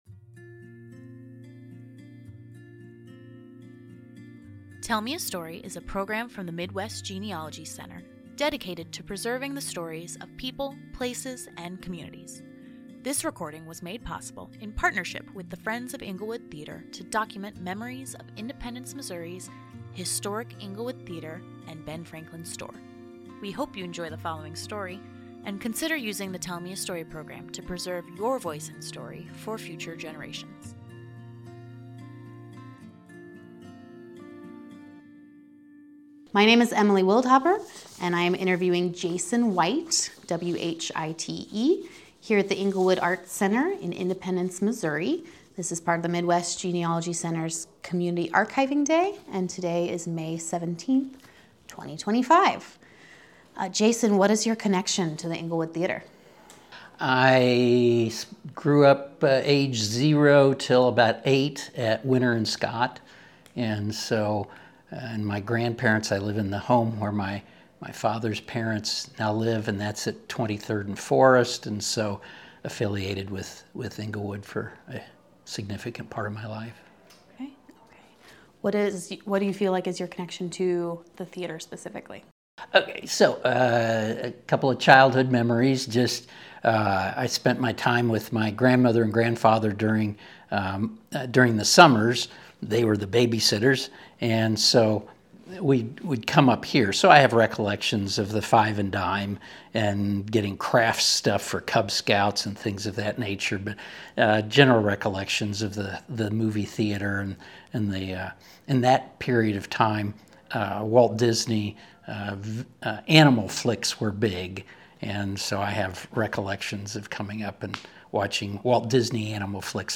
Oral History
Englewood Theater Community Archiving Day - Oral Histories